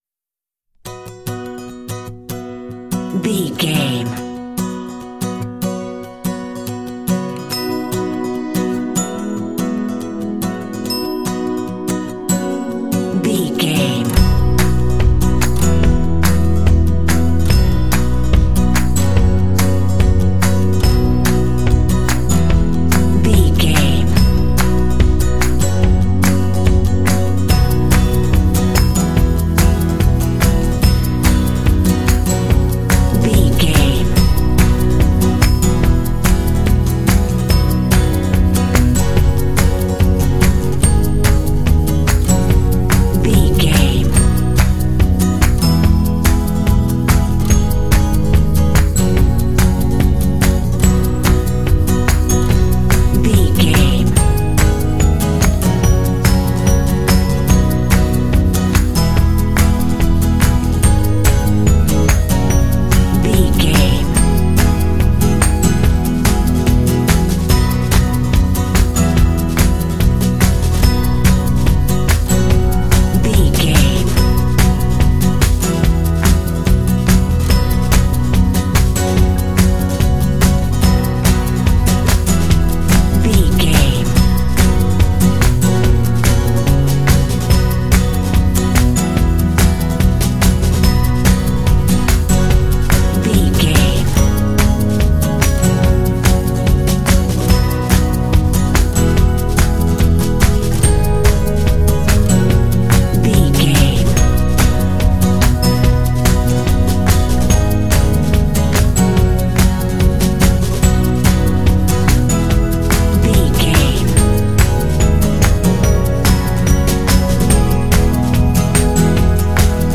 Uplifting
Ionian/Major
Fast
happy
energetic
acoustic guitar
synthesiser
drums
bass guitar
percussion
alternative rock
pop
indie